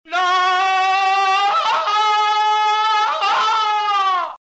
Здесь собраны его знаменитые крики, рычания и другие аудиоэффекты из классических фильмов и мультсериалов.